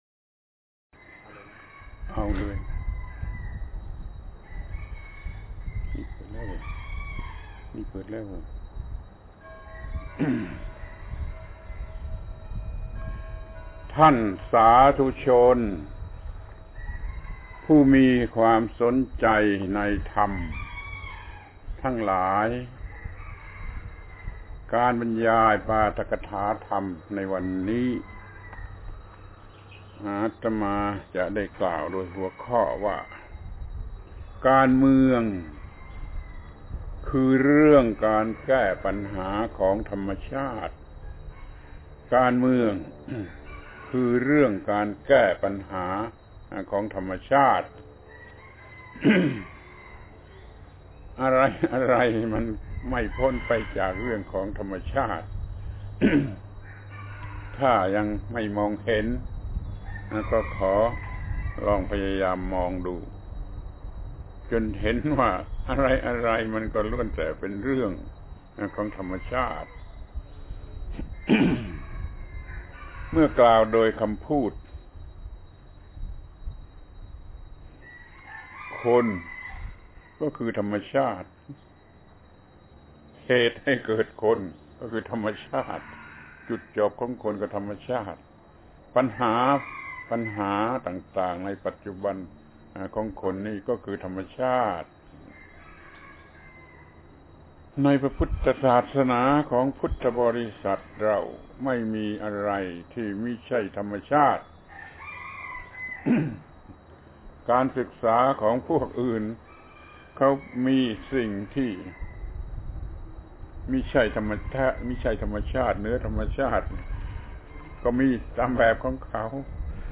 ปาฐกถาธรรมทางวิทยุ ชุด การเมือง การเมืองคือเรื่องการแก้ปัญหาของธรรมชาติ